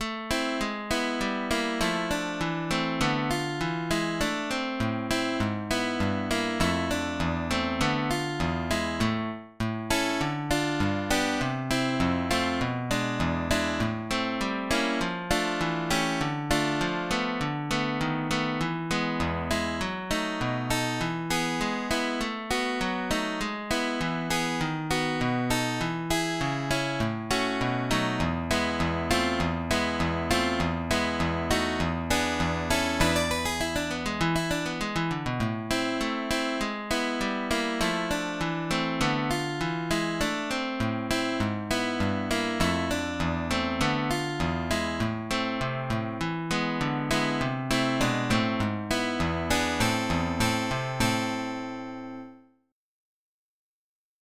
il più importante compositore per chitarra
op 38 – n° 1 – Allegretto – in Do